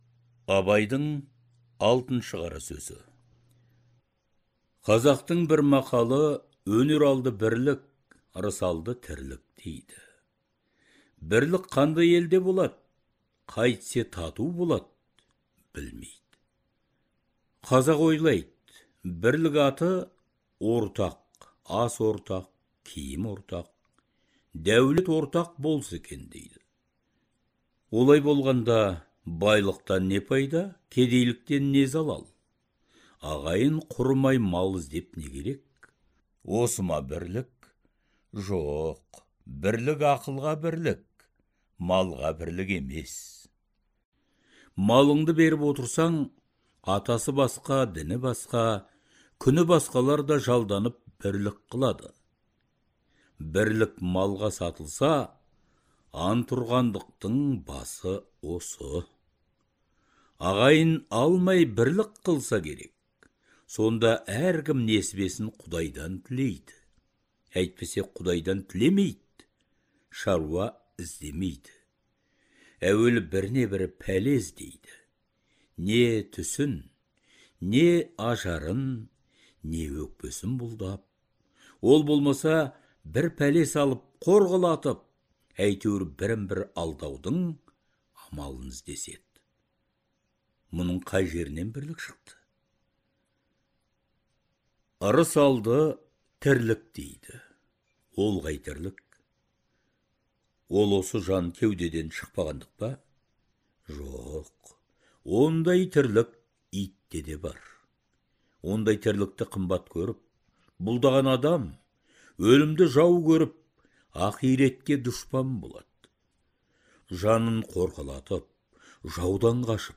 Даналық көсемсөздердің аудио нұсқасы Әдебиет институтының студиясында жазылып алынған.